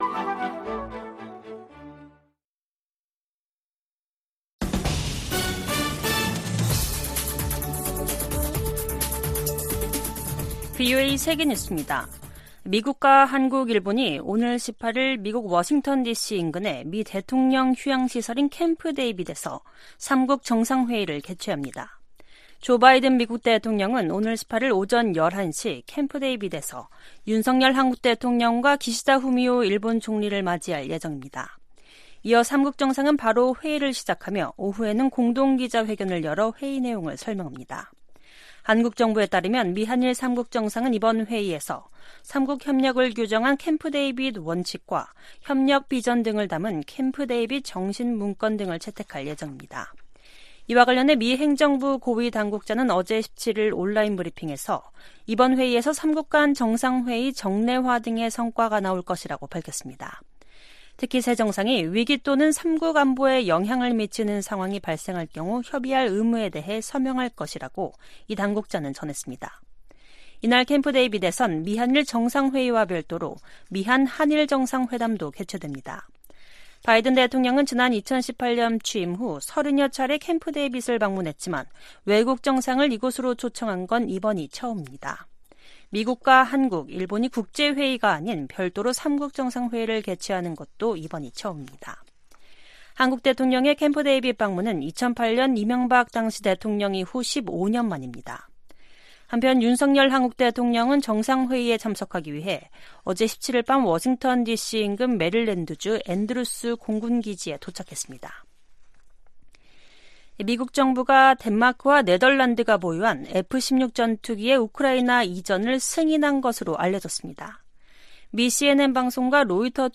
VOA 한국어 간판 뉴스 프로그램 '뉴스 투데이', 2023년 8월 18일 2부 방송입니다. 미한일 캠프데이비드 정상회의에서 공동 안보 협약과 회의 정례화 등 역사적인 성과가 나올 것이라고 미국 고위당국자가 밝혔습니다. 6년 만에 열린 유엔 안보리 북한 인권 공개 논의에서 미국 등 52개국이 별도 성명을 발표했습니다. 미국 정부가 유엔 안보리에서 북한과 러시아 간 무기거래 문제를 제기하는 방안을 모색할 것이라고 유엔주재 미국대사가 밝혔습니다.